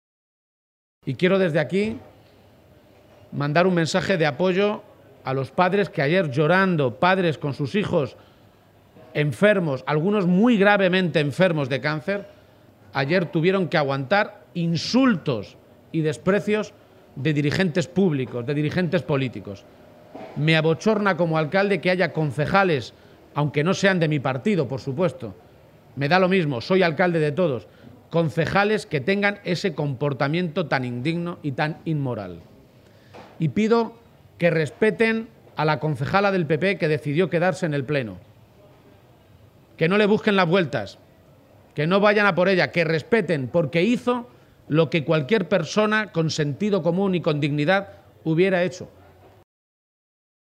García-Page se pronunciaba de esta manera esta mañana en una comparecencia ante los medios de comunicación, durante su visita a las Ferias de Mayo de Talavera.
Cortes de audio de la rueda de prensa